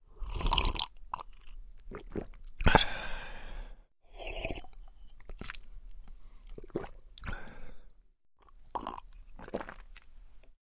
Звуки глотания
Звук мужчина пьет чай или кофе